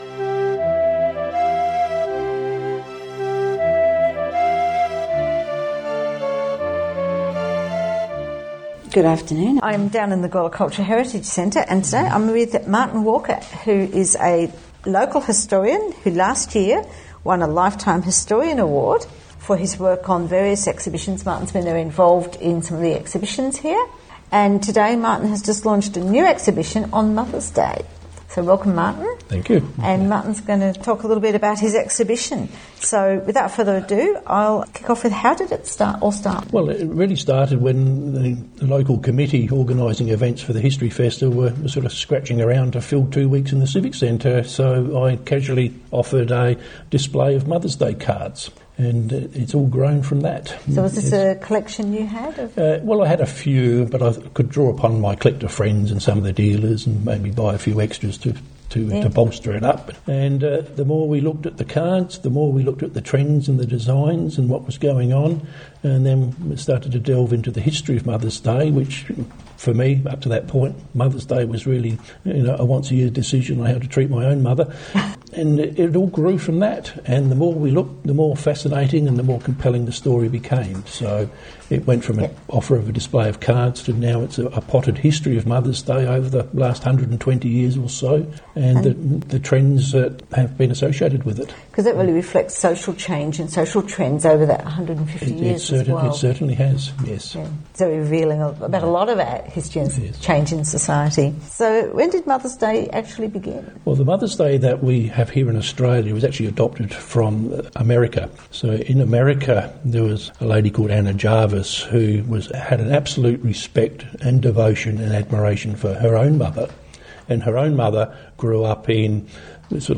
Podcast recorded on Thursday 1st of May at the Gawler Culture and Heritage Centre.